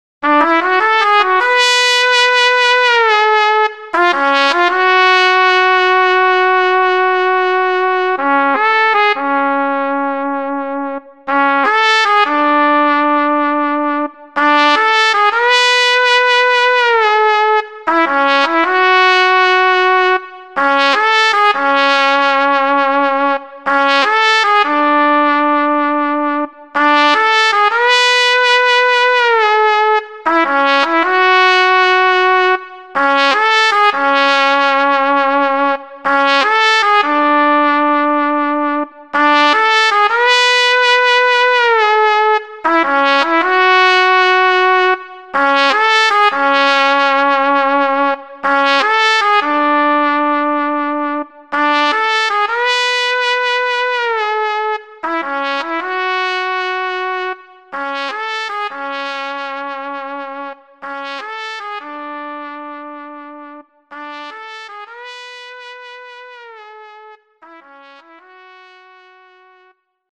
HALion6 : trumpet
Trumpet Vibrato Dynamic Full